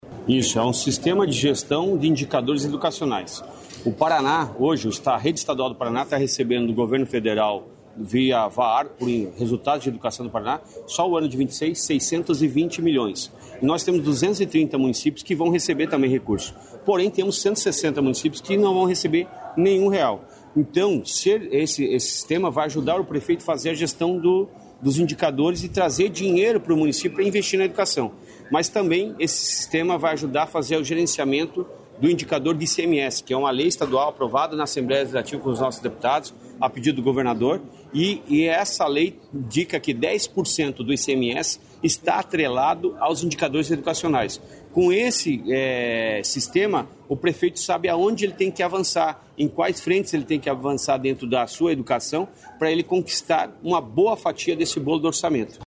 Sonora do secretário da Educação, Roni Miranda, sobre ferramenta para fortalecer gestão e ampliar resultados na educação